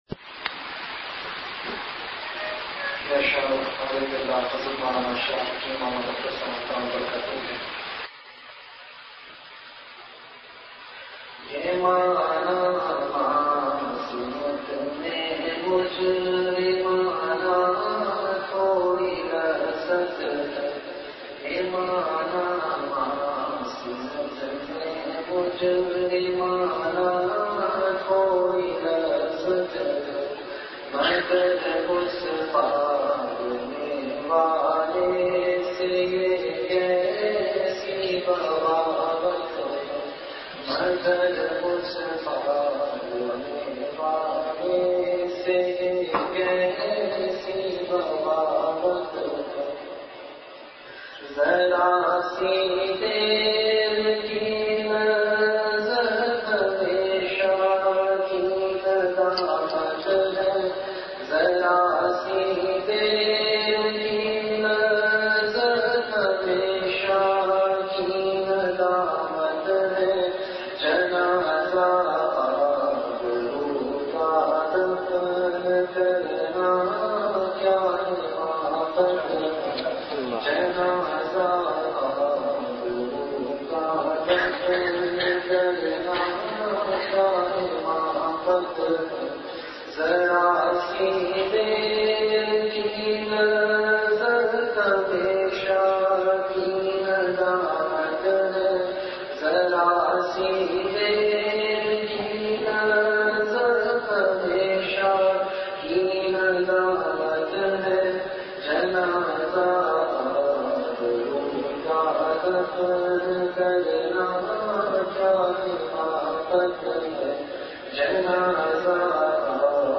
Majlis-e-Zikr
After Isha Prayer